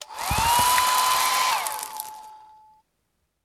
drytool.ogg